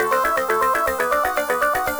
Index of /musicradar/8-bit-bonanza-samples/FM Arp Loops
CS_FMArp A_120-A.wav